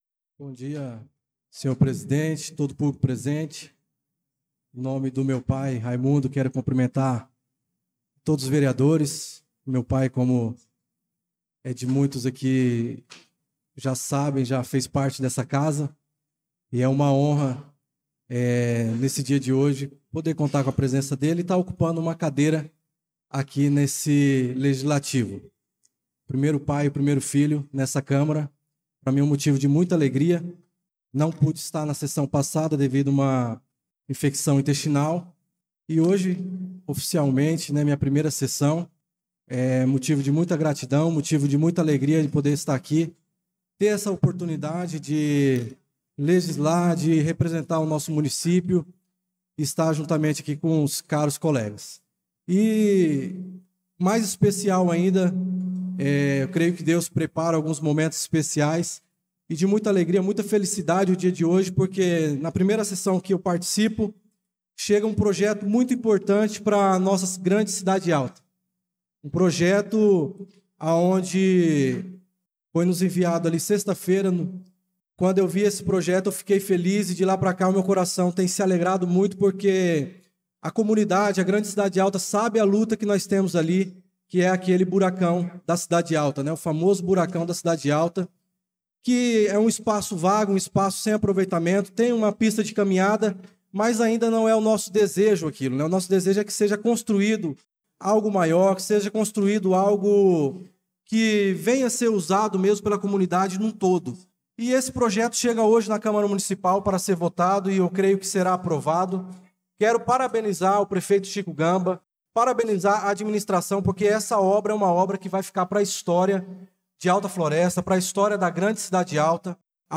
Pronunciamento do vereador Darlan Carvalho na Sessão Extraordinára do dia 11/02/2025